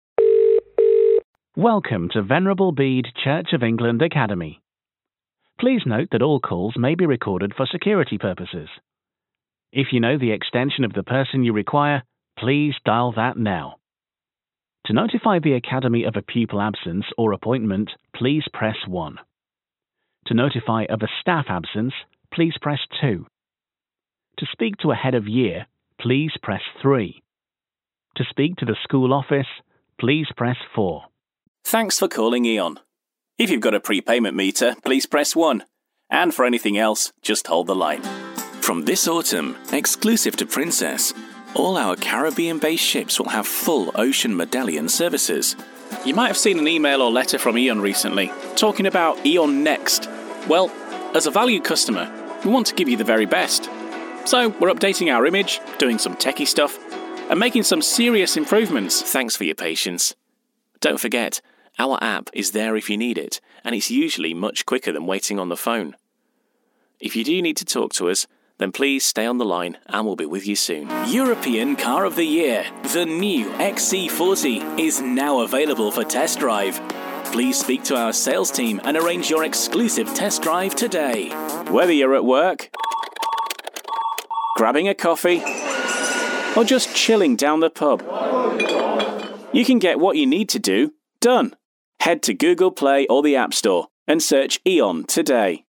Commercieel, Stoer, Vriendelijk, Warm, Zakelijk
Telefonie
Fris, warm, zacht, vriendelijk en conversatieel
Neutraal, met Received Pronunciation accent
Zowel neutraal Noord-Engels als een echt Yorkshire accent (West Yorkshire/Leeds)